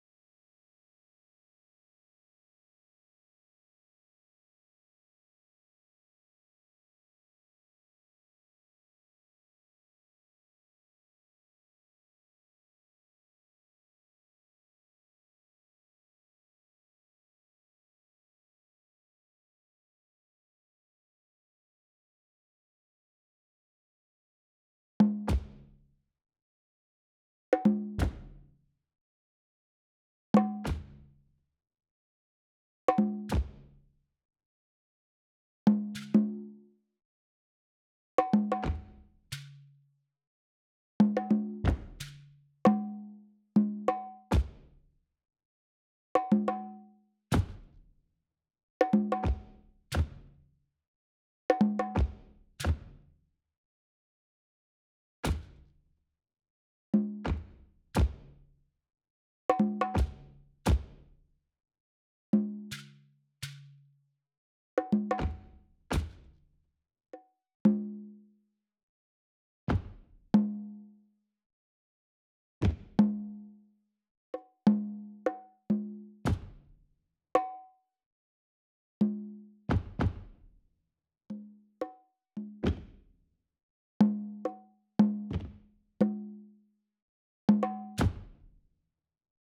90 BPM
Coffee Shop percussion 68385_Coffee Shop.wav